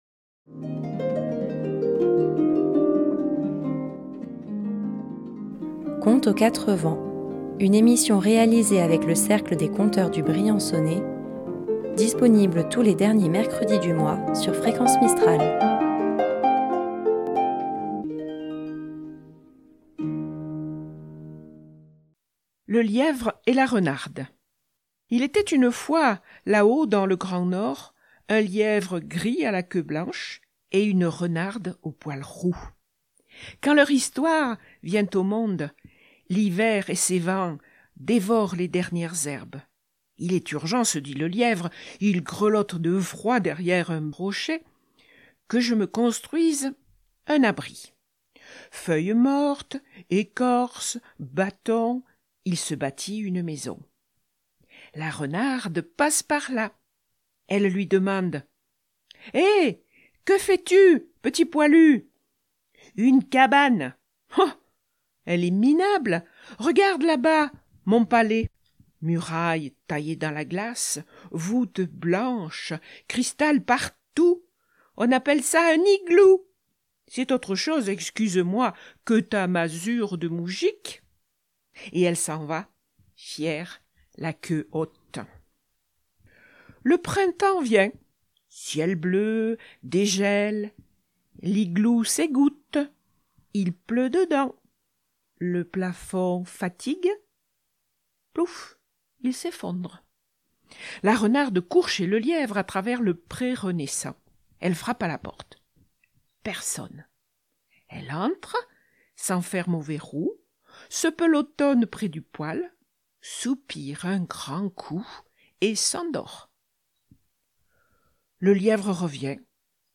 Tous les derniers mercredi du mois, à 18h11, retrouvez le Cercle des conteurs du Briançonnais pour une balade rêveuse.